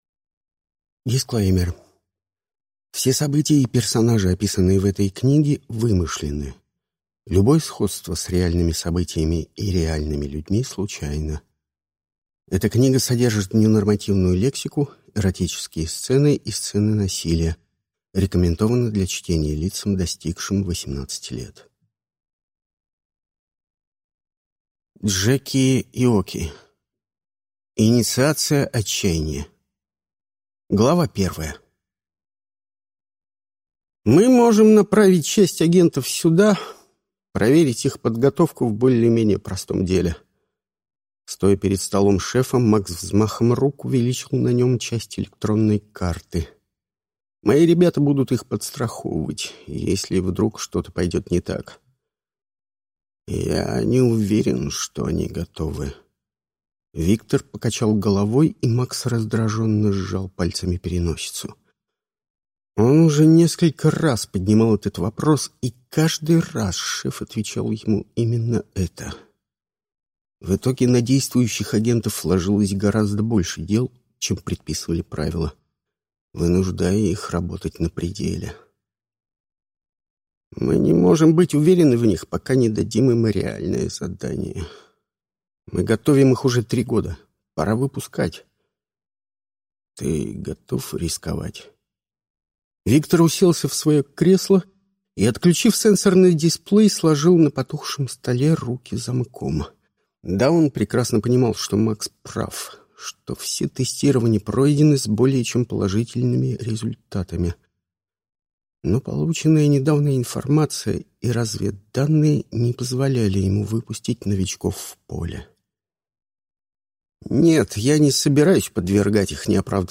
Аудиокнига Инициация «Отчаяние» | Библиотека аудиокниг